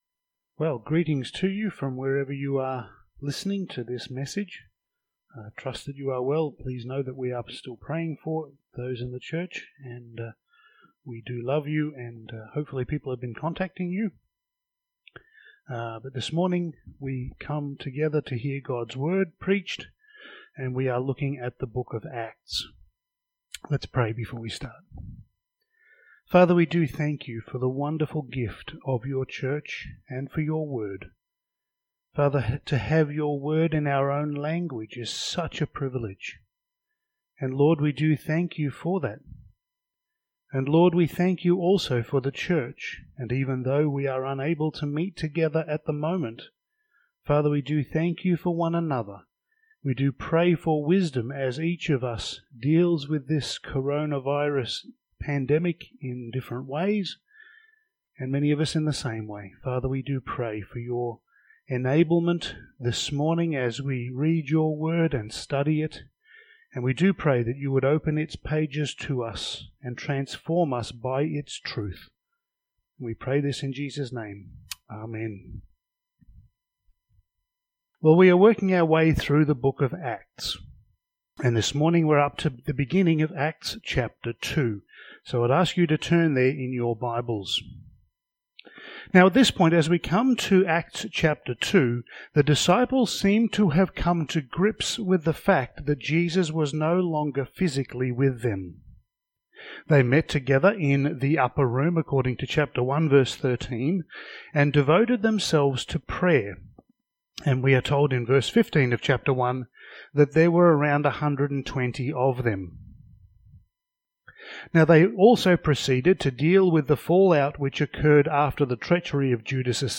Passage: Acts 2:1-13 Service Type: Sunday Morning